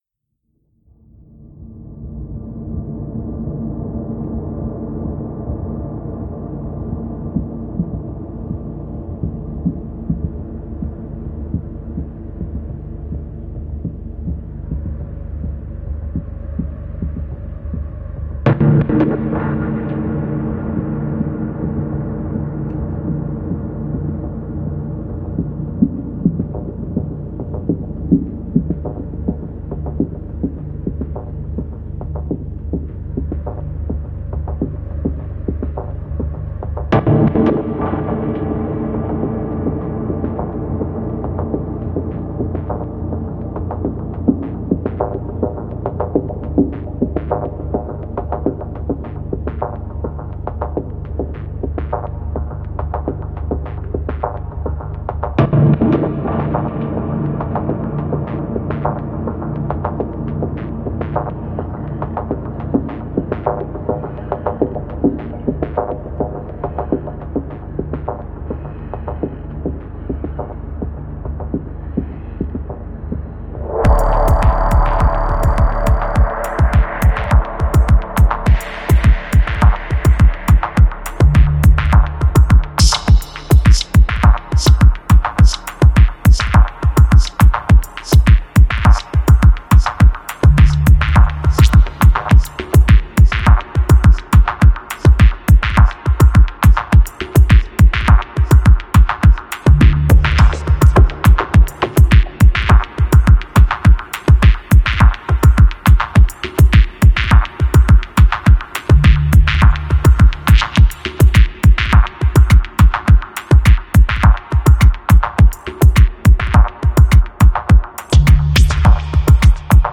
Genre: Dub Techno/Ambient/Tech House.